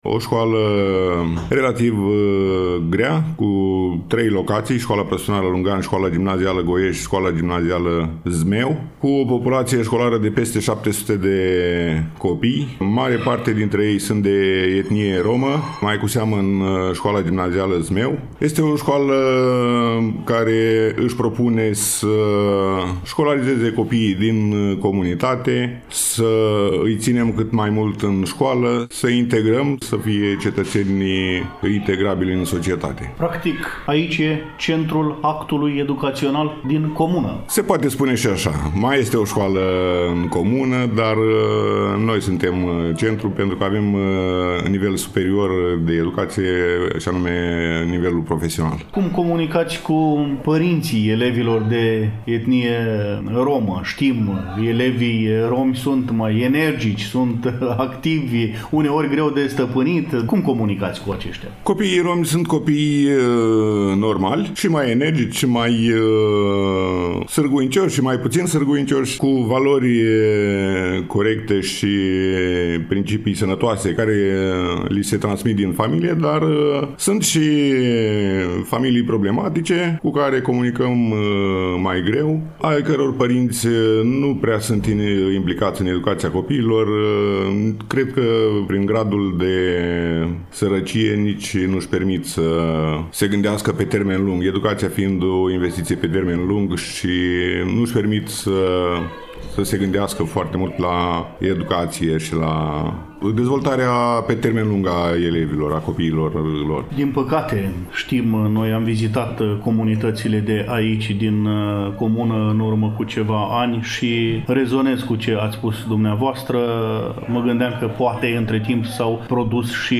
Am făcut popas în incinta Școlii Profesionale din Lungani, acolo unde reprezentanții au prezentat tinerilor romi din comună proiectul Suntem activi și împlicați în comunitatea noastră.